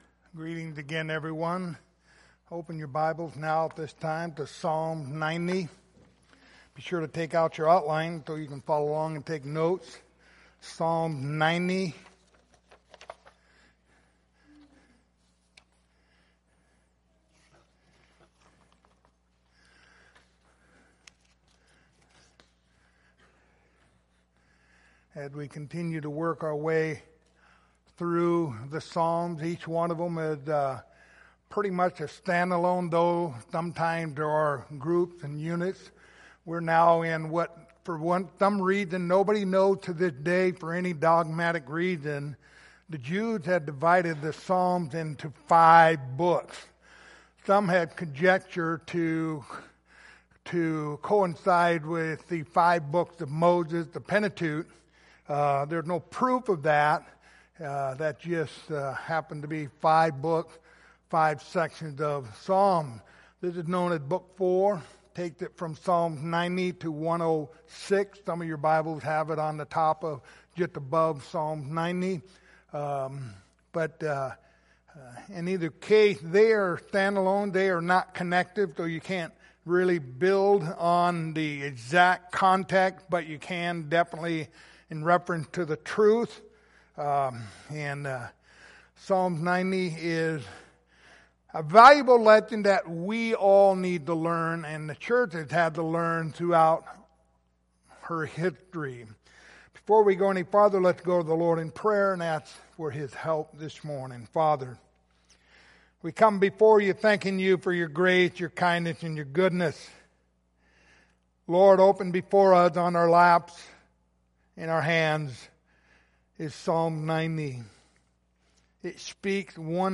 The book of Psalms Passage: Psalms 90:1-17 Service Type: Sunday Morning Topics